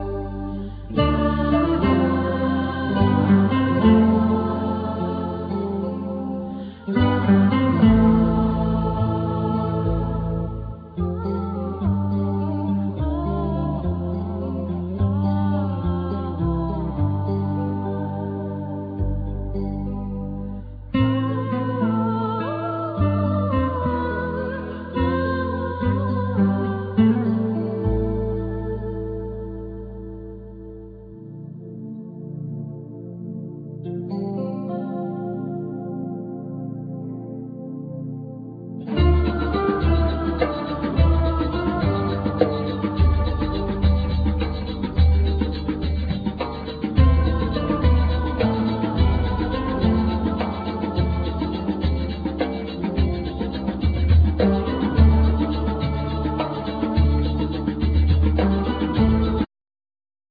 Cretan lyra
Duduk,Shevi
Nylon string guitar,Mandokino,Bass
Piano,Keyboards